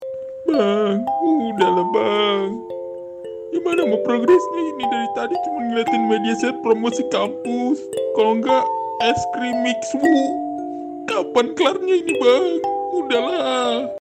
Efek suara Bang udah bang
Kategori: Suara viral